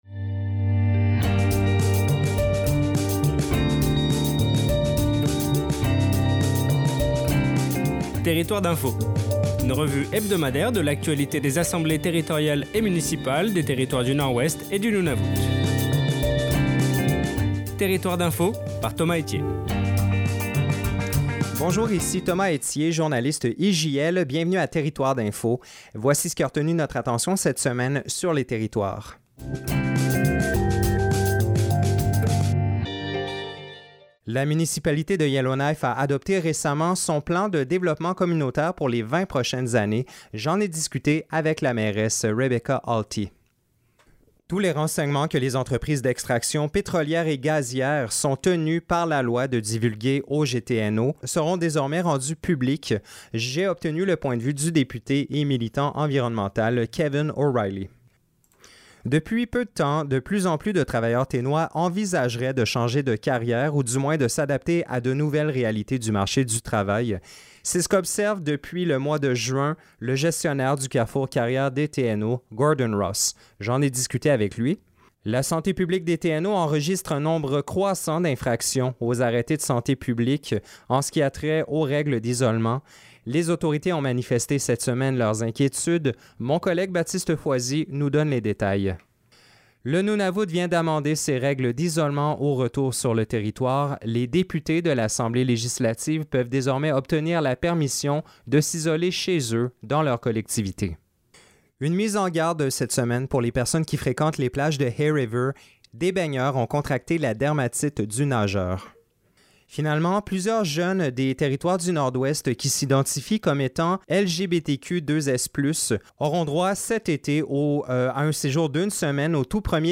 7 août 2020 Territoires d'info, le bulletin IJL hebdomadaire de Radio Taïga